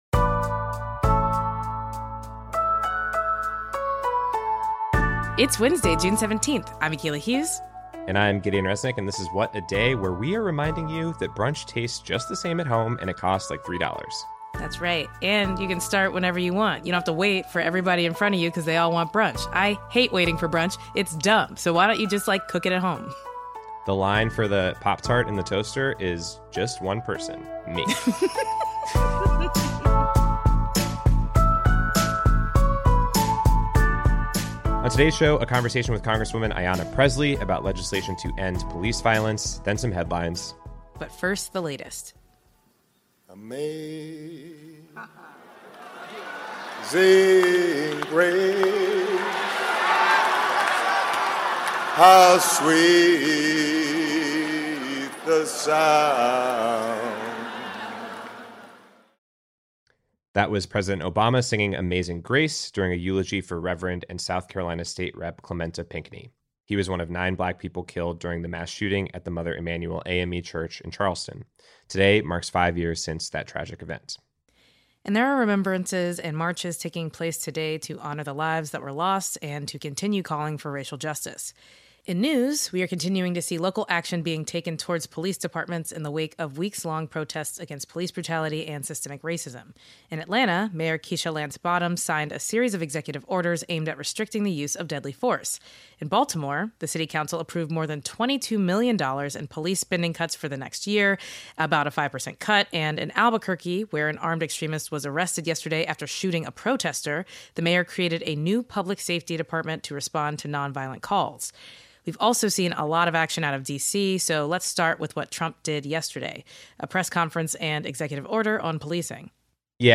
We interview Massachusetts Rep. Ayanna Pressley about federal legislation on policing, and why ending qualified immunity is a central goal.